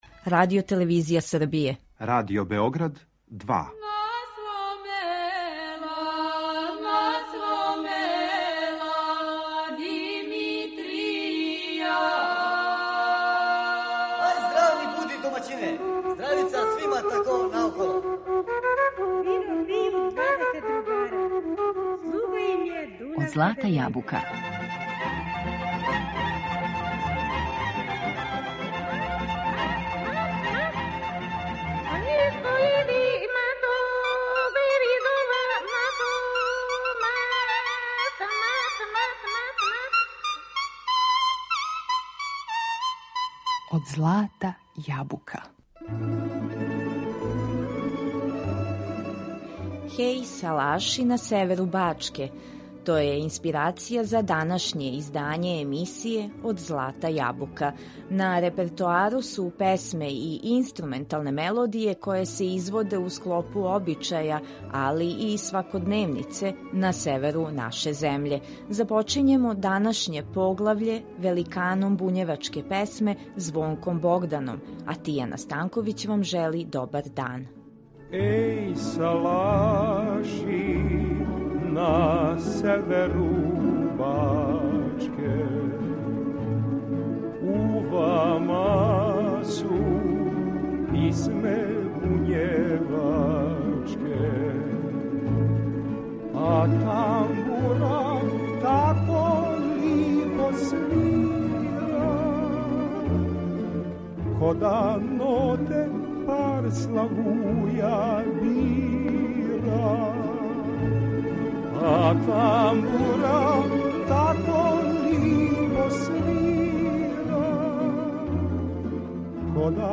На репертоару су песме и инструменталне мелодије које се изводе у склопу обичаја, као и свакодневице, на северу наше земље. Чућете Суботички тамбурашки оркестар и вокалне извођаче најлепших тамбурашких песама које се певају у околини Суботице.